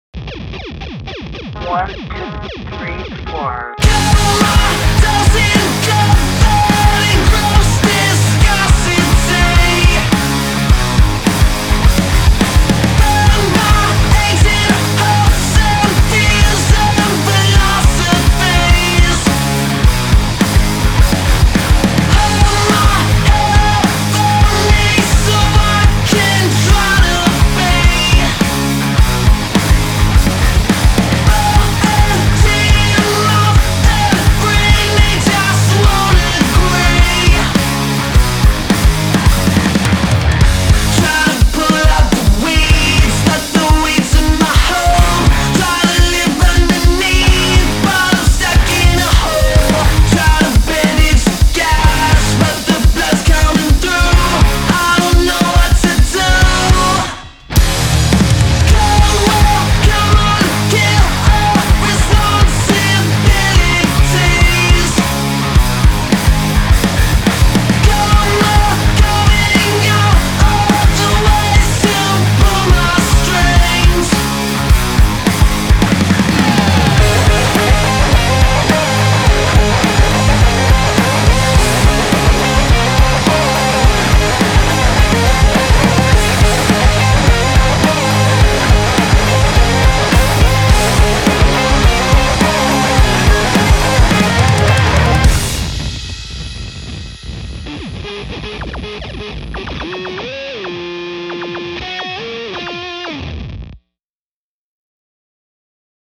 Альбом относится к жанрам альтернативный/инди-рок, поп-панк.